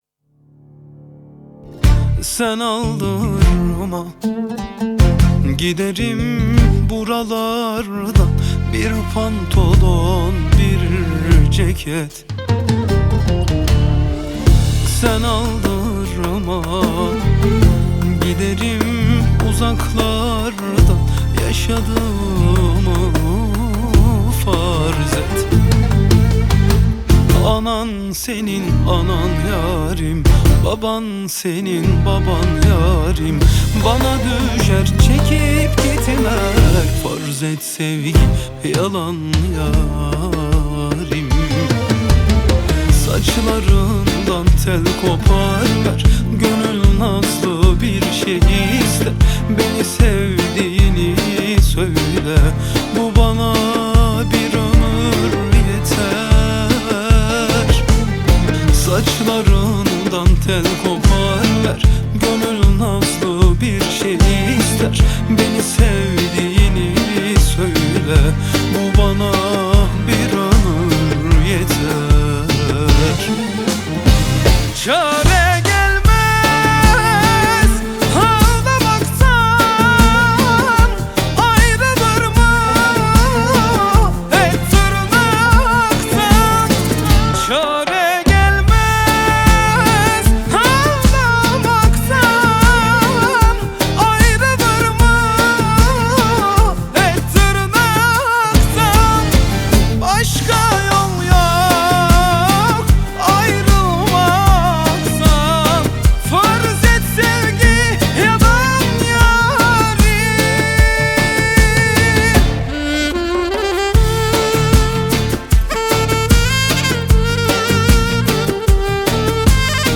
Kemanlar
Gitarlar
Percussion
Bağlama, Ud
Ney
Saksafon